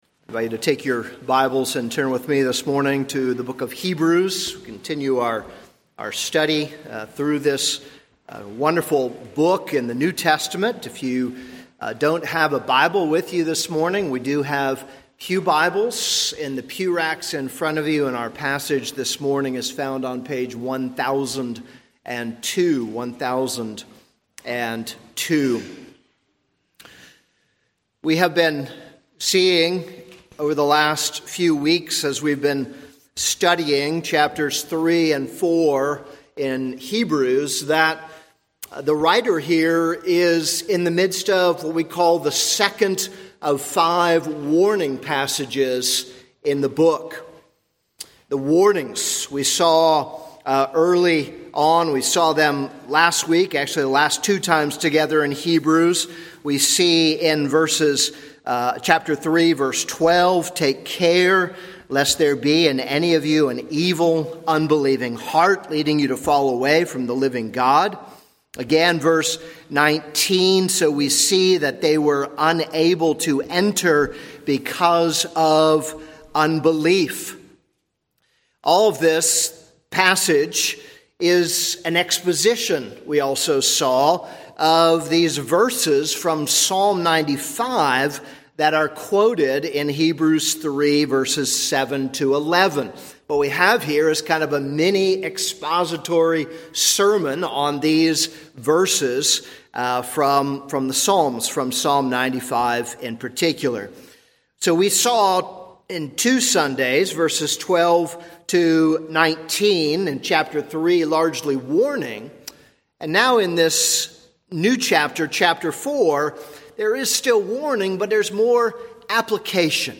This is a sermon on Hebrews 4:1-13.